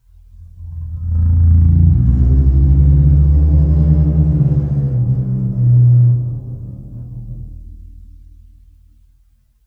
bassdrum_rub3_v1.wav